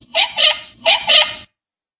标签： 打闹-声音 漫画 吱吱响 移动 游戏 螺纹连接 吱吱 车削
声道立体声